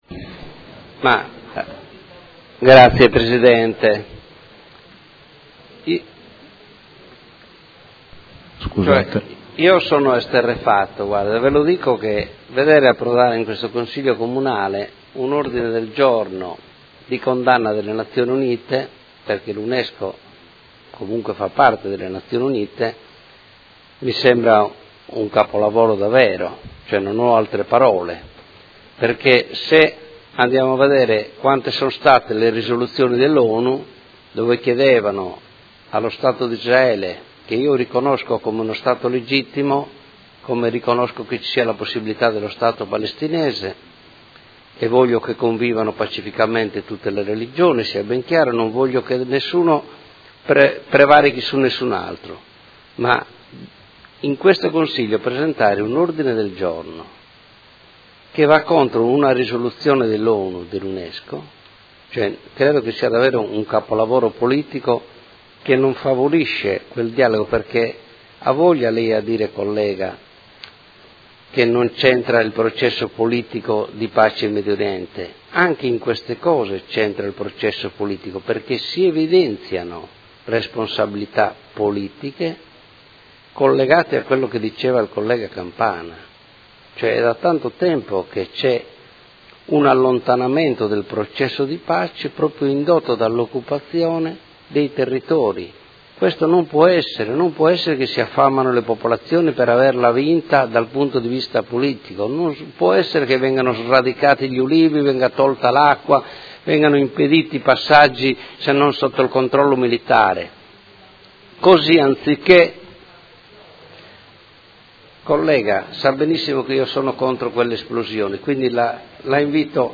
Marco Cugusi — Sito Audio Consiglio Comunale